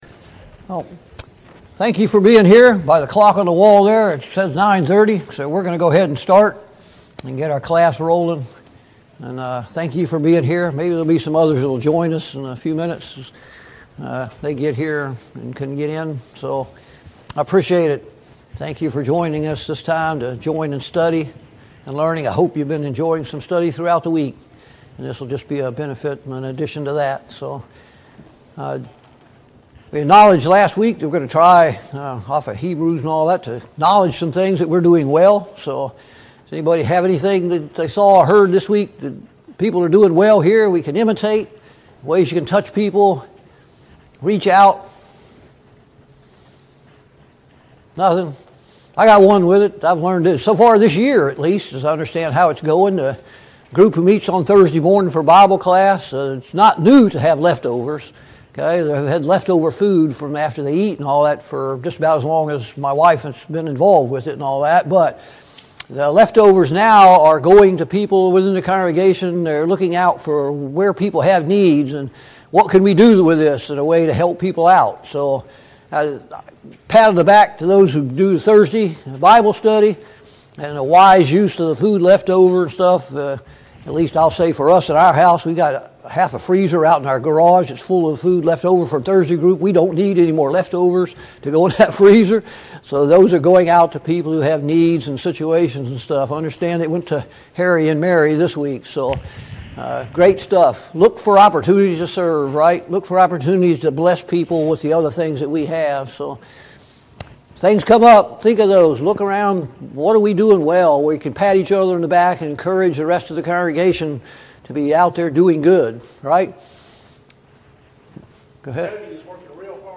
Passage: Jeremiah 3 Service Type: Sunday Morning Bible Class « Study of Paul’s Minor Epistles